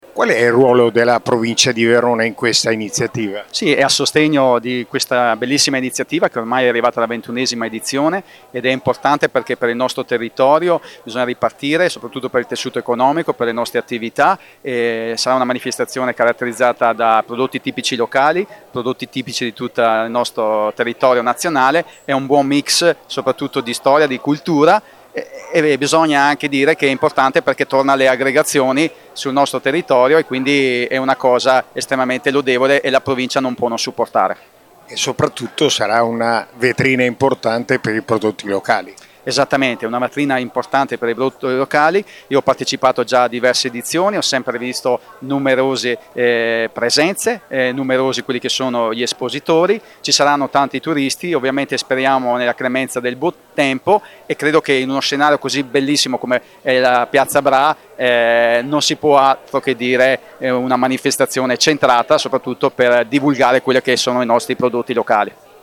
ha raccolto le dichiarazioni nella giornata di presentazione dell’evento:
David Di Michele, Vice Presidente della Provincia di Verona
David-Di-Michele-Vice-Presidente-della-Provincia-di-Verona.mp3